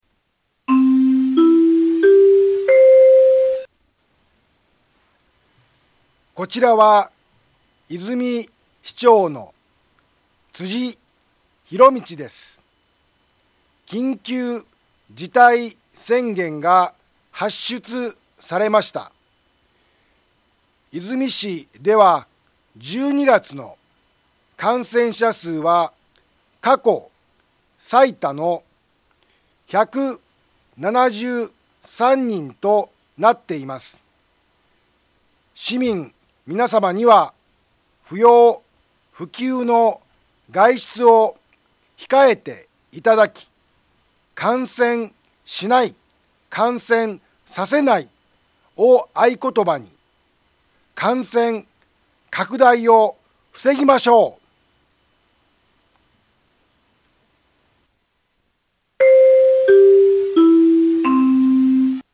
BO-SAI navi Back Home 災害情報 音声放送 再生 災害情報 カテゴリ：通常放送 住所：大阪府和泉市府中町２丁目７−５ インフォメーション：こちらは、和泉市長の辻 ひろみちです。 緊急事態宣言が発出されました。 和泉市では１２月の感染者数は過去最多の１７３人となっています。